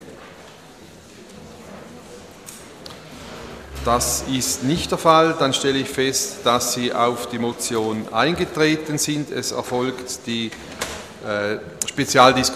Audiodatei Session des Kantonsrates vom 2. bis 4. Juni 2014